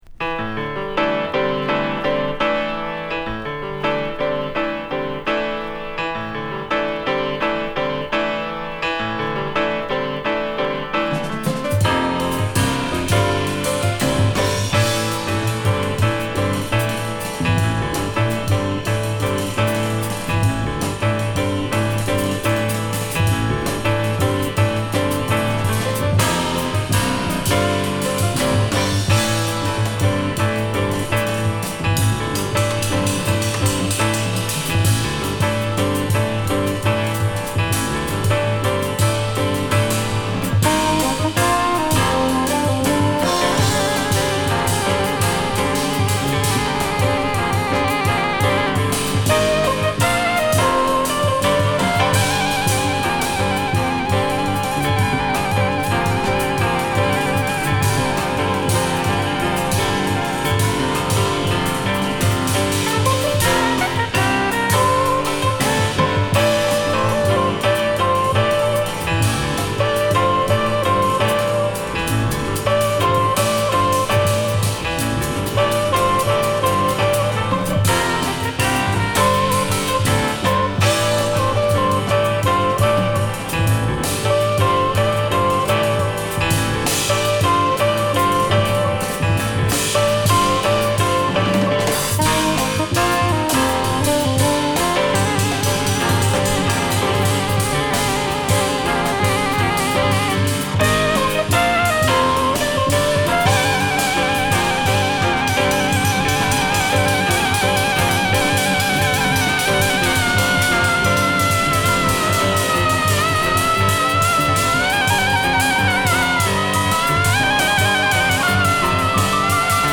コルトレーン一派らしいアーシーなスピリチュアル感を持ったストレートなジャズアルバム！